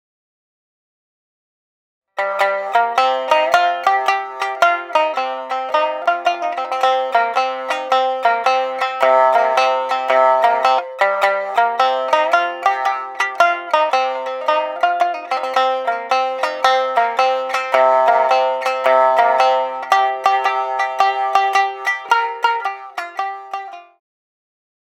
traditional Japanese folk song
• Tuning: Honchōshi
• Techniques: sukui, hajiki, tsuretara, maebachi